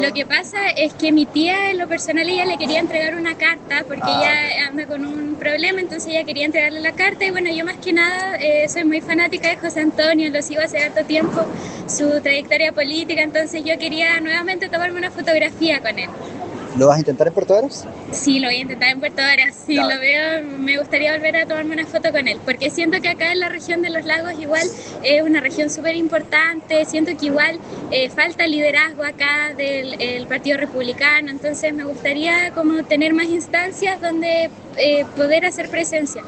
En tanto, entre quienes no lograron dialogar con el presidente electo se encontraba una familia, dentro de la cual había una joven simpatizante del Partido Republicano, quien conversó con La Radio. La adherente lamentó no haber podido fotografiarse con el próximo mandatario y aseguró que continuará intentando encontrarse con él en Puerto Varas.